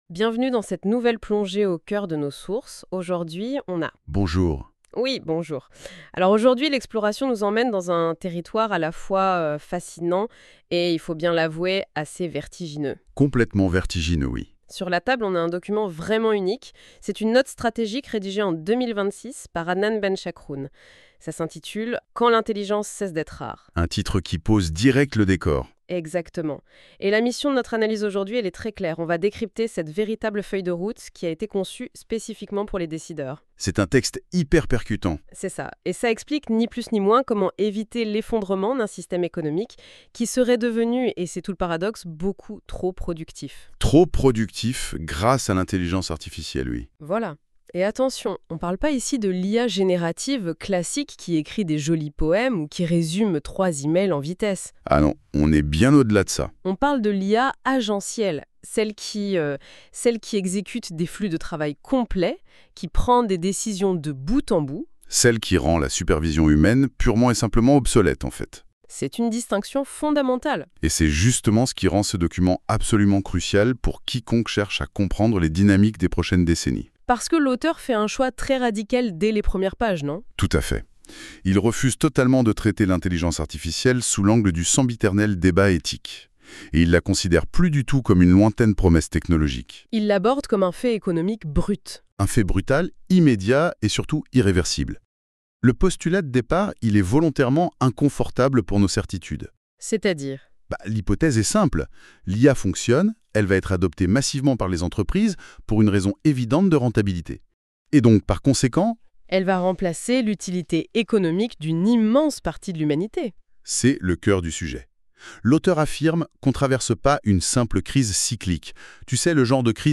Débat - Podcast à écouter ici (22.09 Mo)